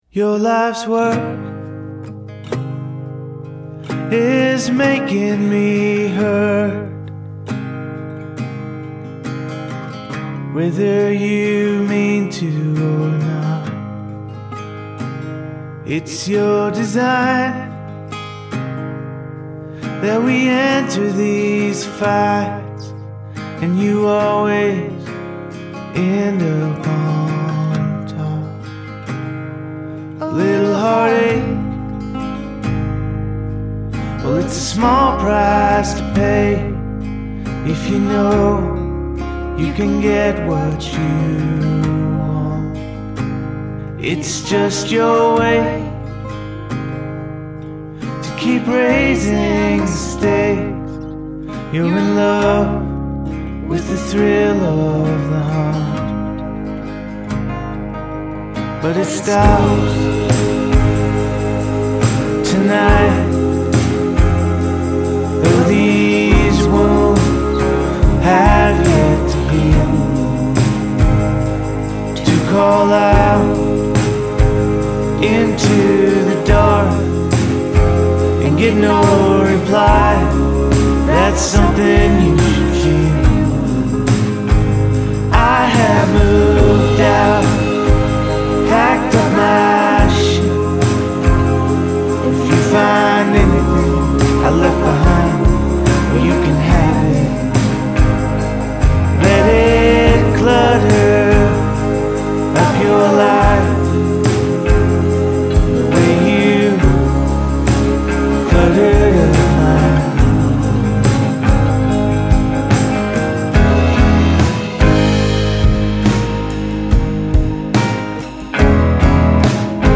but it is deeply wistful and bittersweet